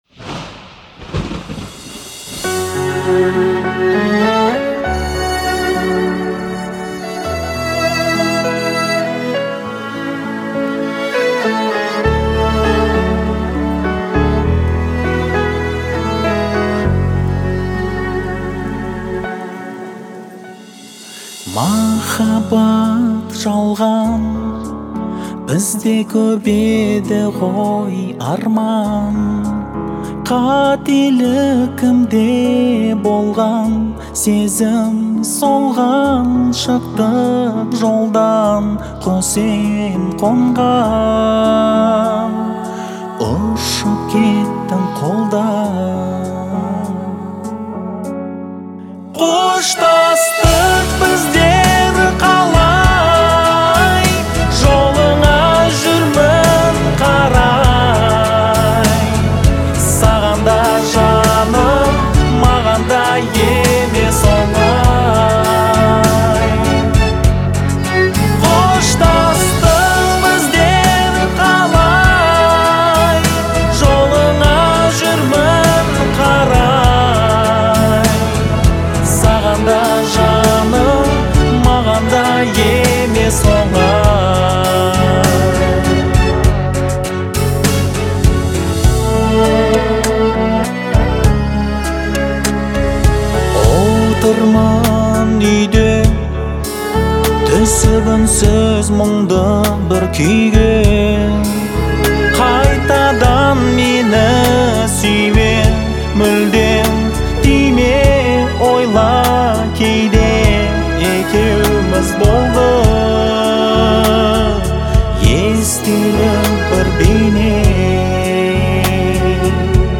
сочетая мягкий голос с мелодичными инструментами.